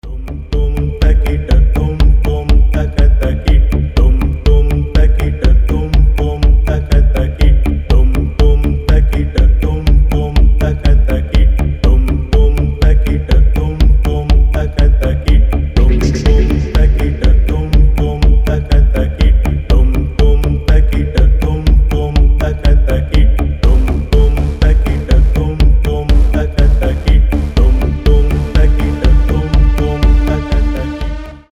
ритмичные
атмосферные
Electronic
Техно
минимал